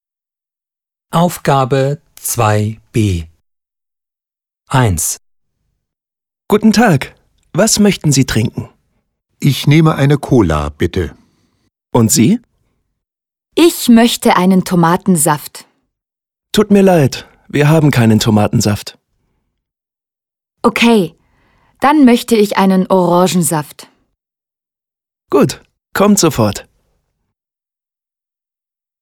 Dialog 1: